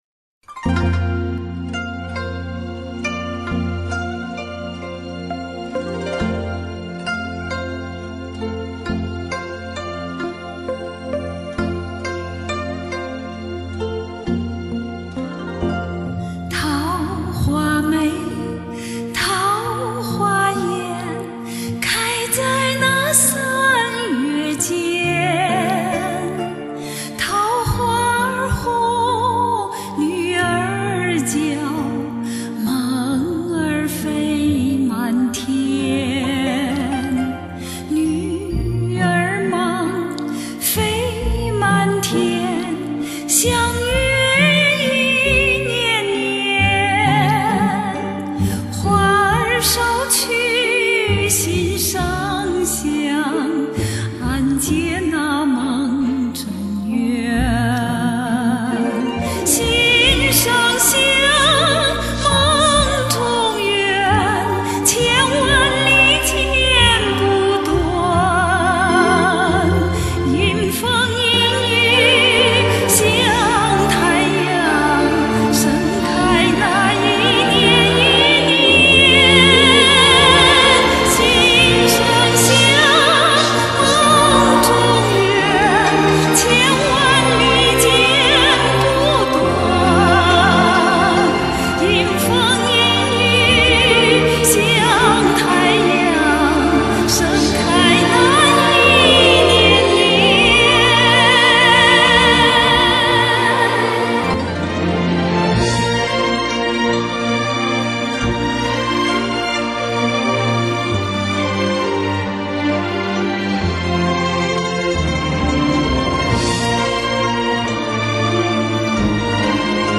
情深，柔美，好聽